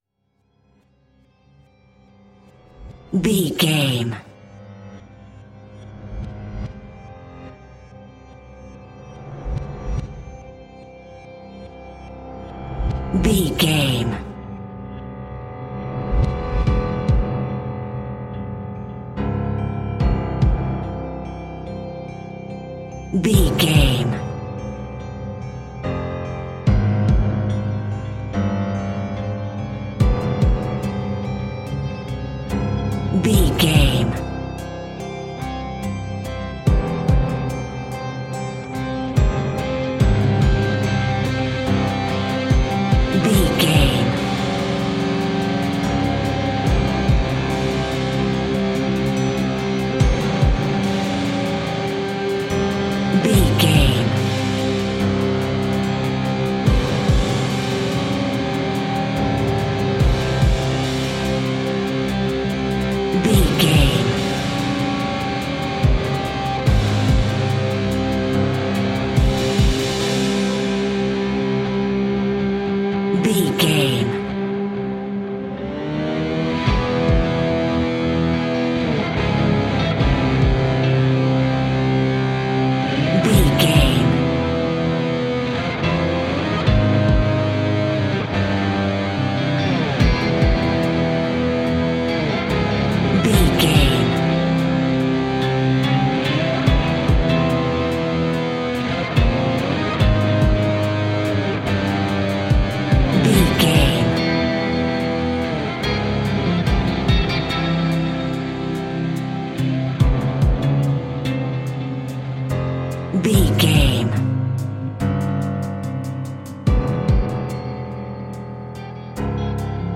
Fast paced
Ionian/Major
industrial
dark ambient
EBM
drone
synths
Krautrock